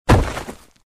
collapse2.wav